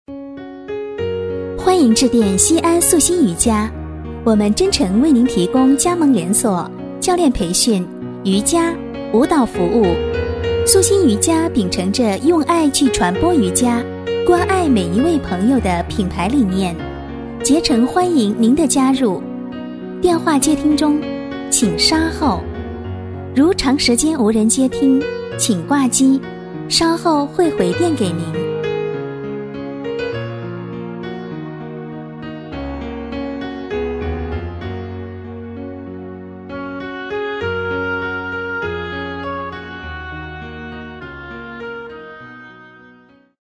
女声配音
彩铃女国90